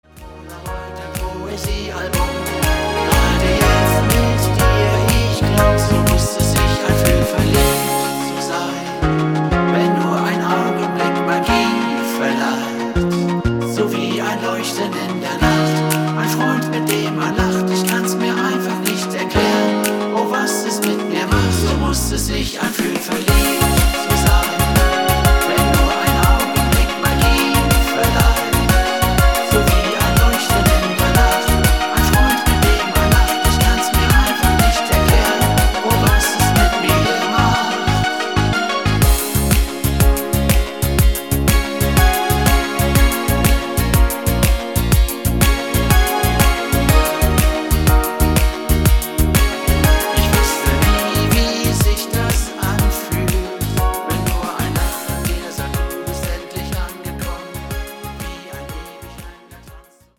Duett